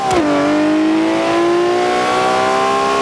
Index of /server/sound/vehicles/lwcars/lam_reventon